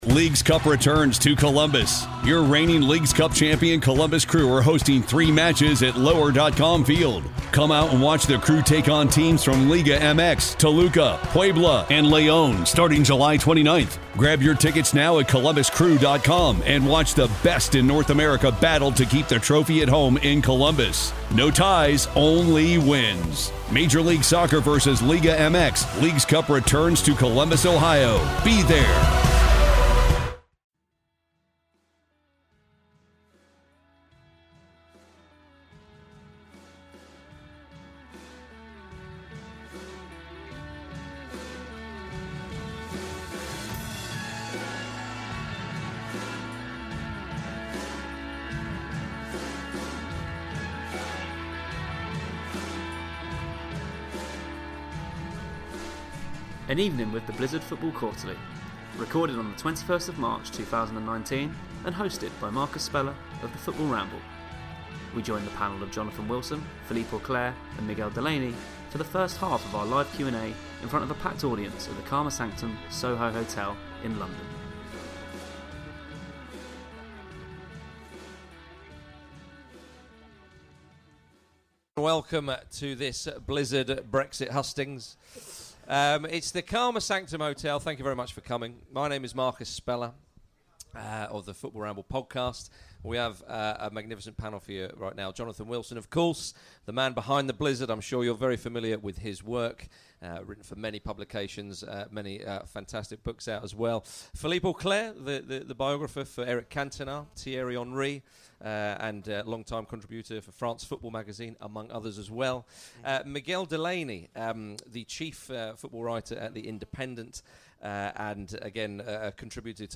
The audio recording from the first half of our event in London on Thursday 21st March.